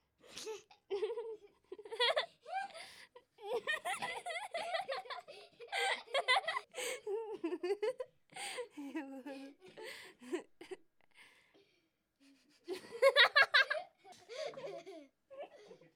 young girls giggling and laughing